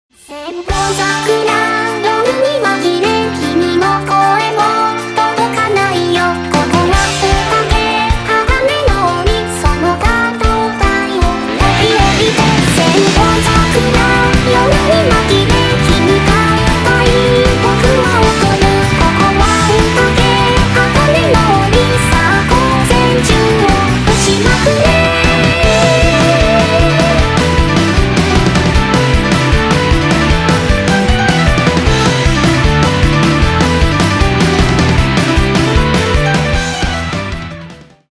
Вокалоиды